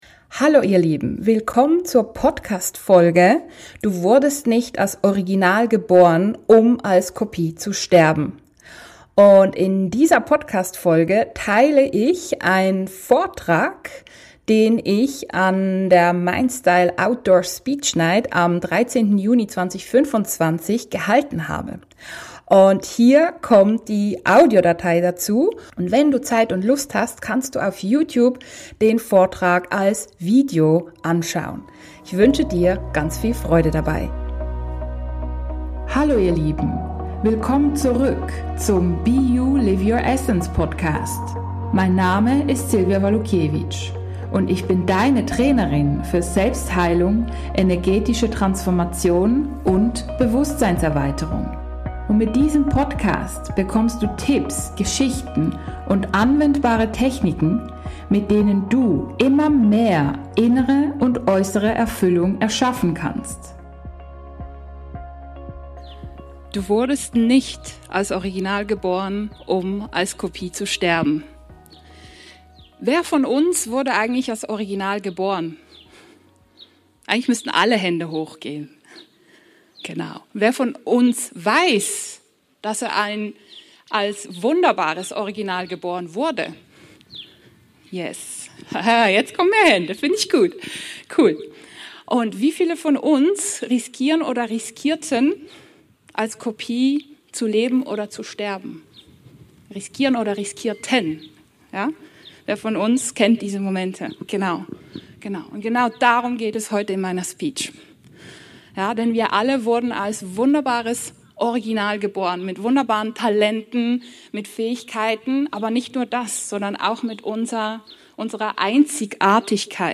Du wurdest nicht als Original geboren, um als Kopie zu sterben. Darüber habe ich am 13. Juni 2025 bei der Mindstyle.Speech.Night gesprochen – outdoor, also komplett draussen!